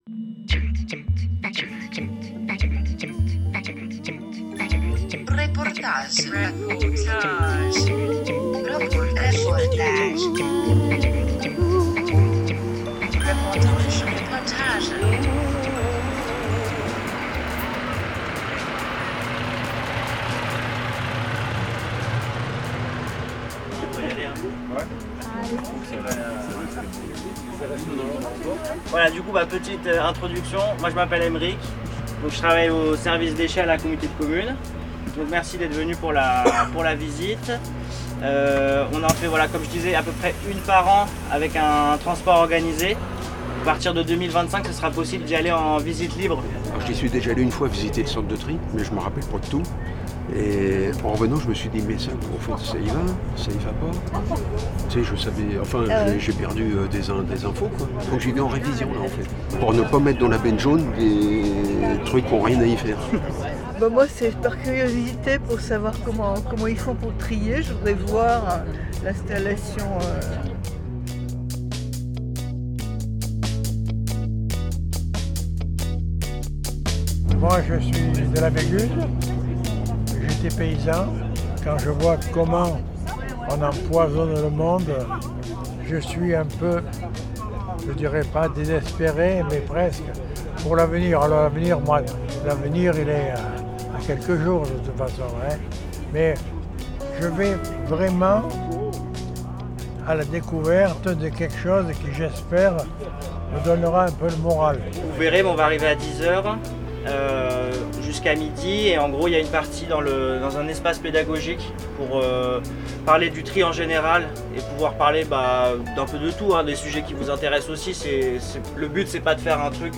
14 mars 2025 10:56 | Interview, reportage
A-la-poursuite-de-la-poubelle-jaune_reportage-a-Metripolis_PAD.mp3